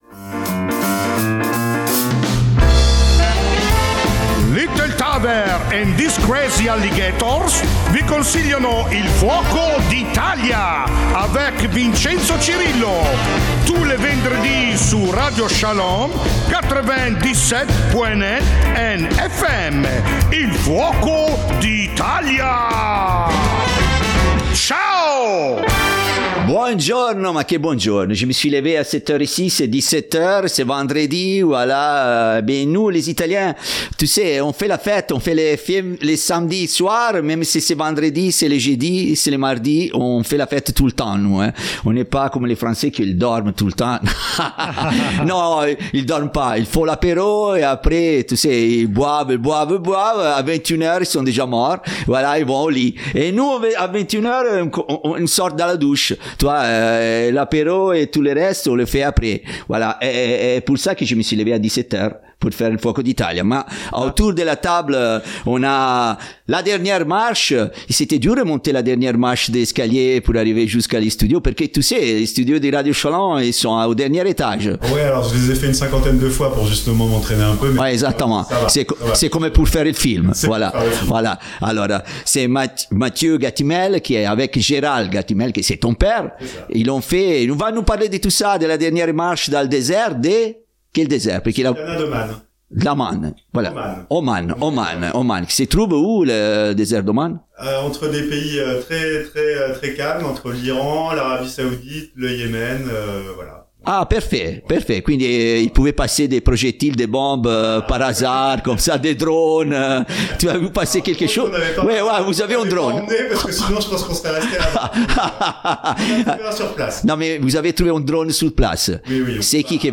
Nous sommes navré par la qualité de certains passages, un des micros nous à laché en plein enregistrement.